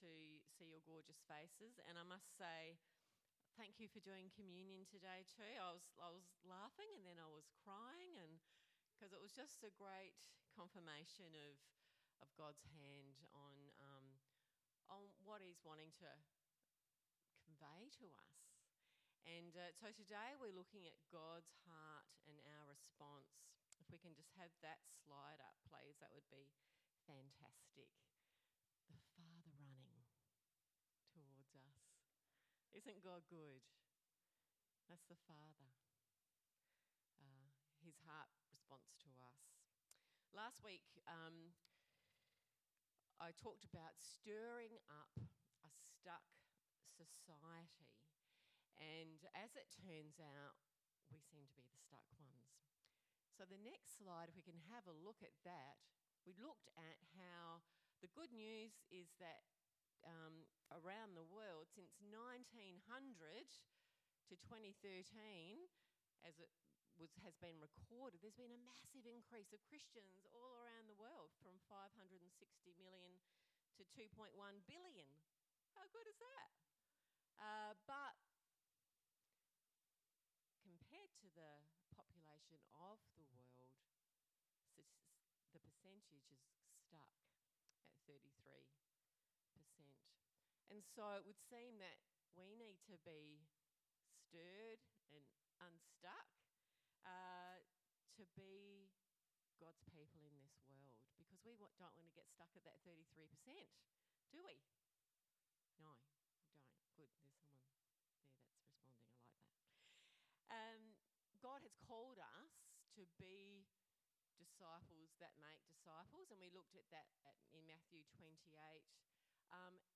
Sermons | Marion Vineyard Christian Fellowship